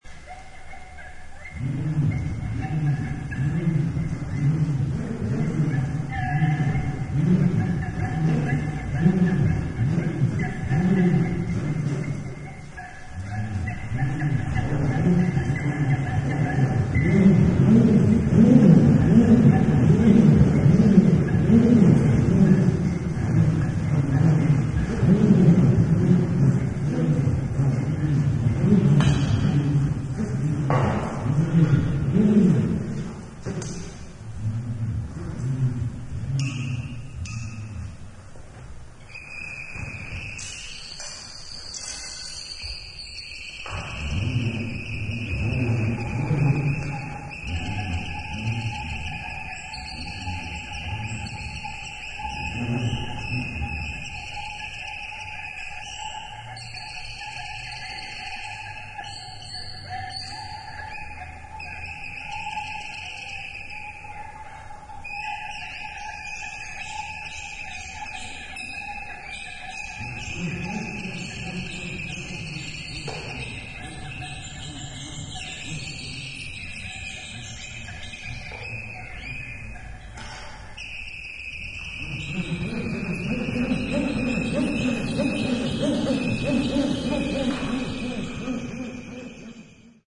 Audience Side